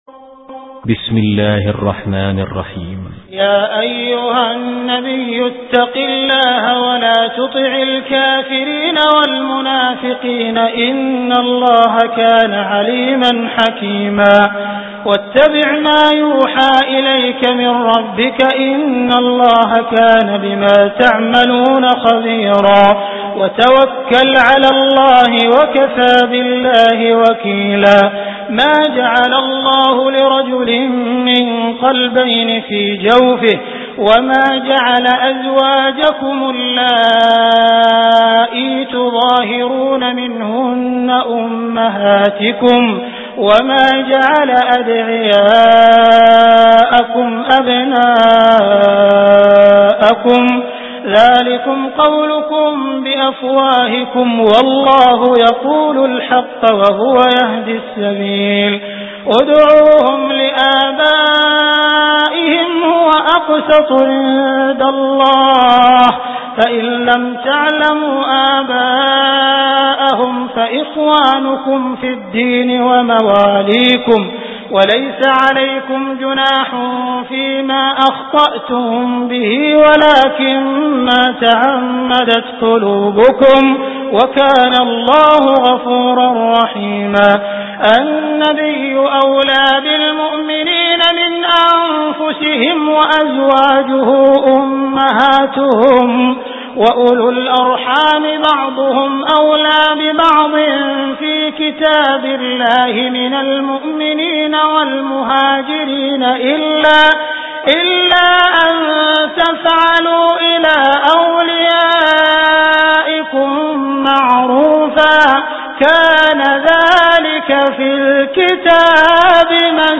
Surah Al Ahzab MP3 Download By Abdul Rahman Al Sudais. Surah Al Ahzab Beautiful Recitation MP3 Download By Abdul Rahman Al Sudais in best audio quality.